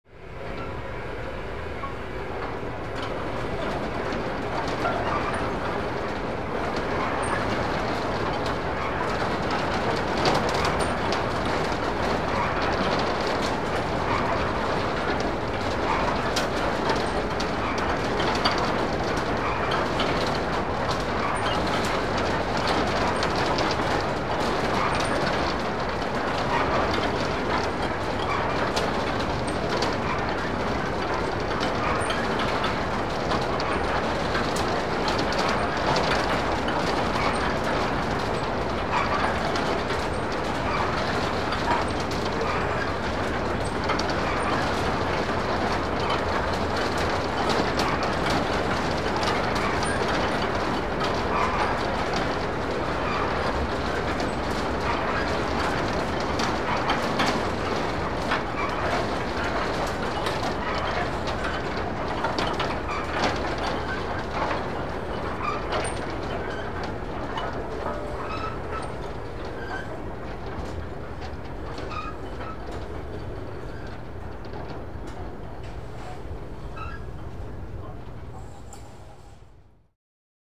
ambience
Carousel Ride - Operates with Metallic, Wooden Clanks